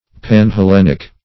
Panhellenic \Pan`hel*len"ic\, a. [See Panhellenium.]